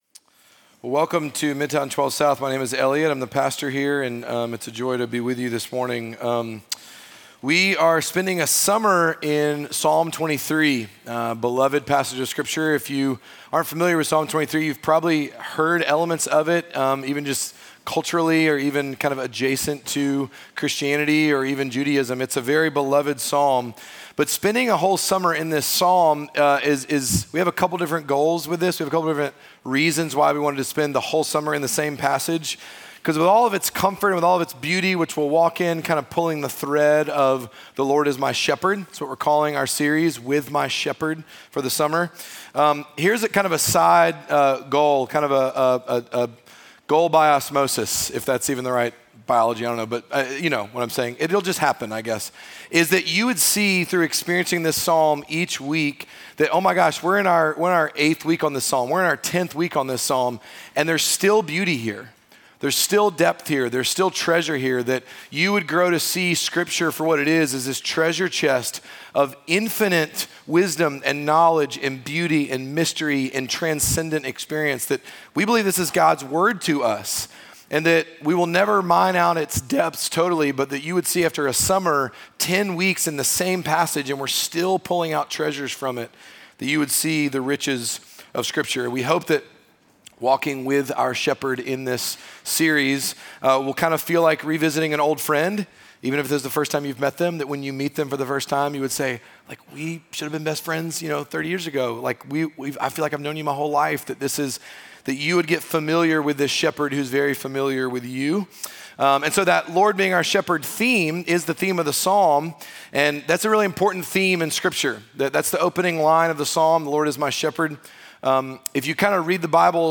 Midtown Fellowship 12 South Sermons